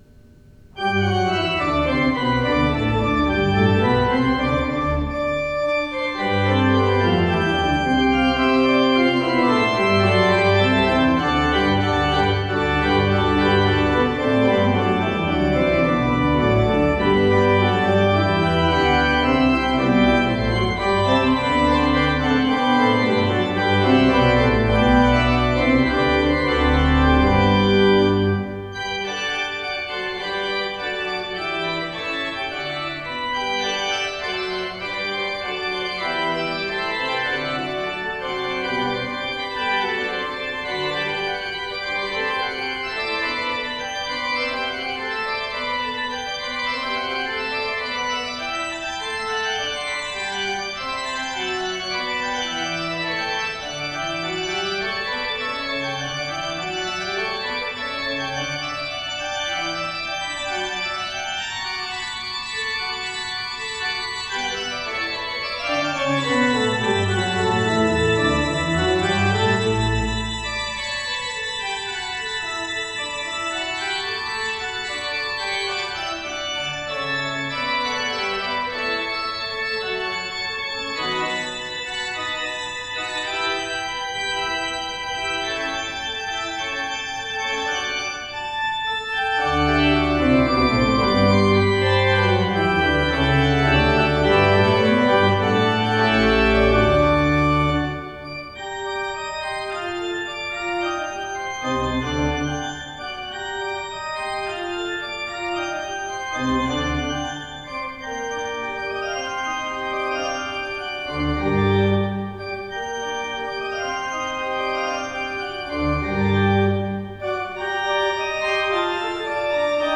Postludes played at St George's East Ivanhoe 2024
The performances are as recorded on the Thursday evening prior the service in question and are recorded direct to PC using a Yeti Nano USB microphone..